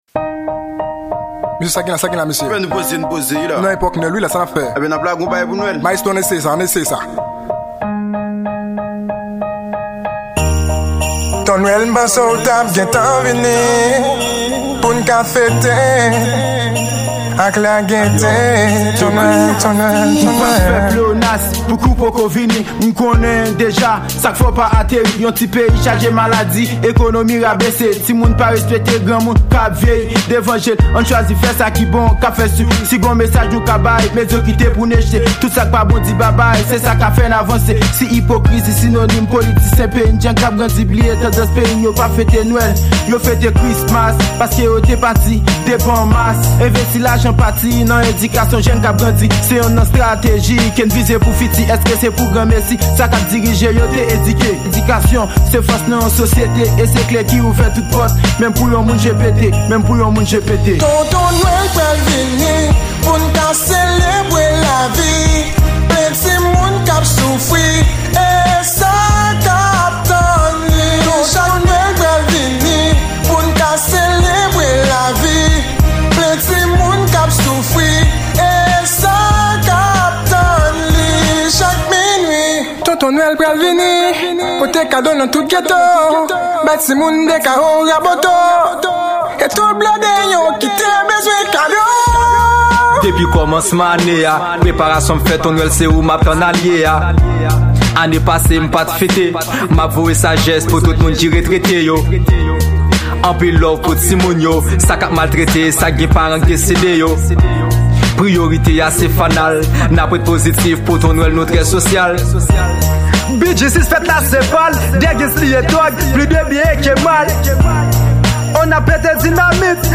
Date de sortie: 2017 Genre: Rap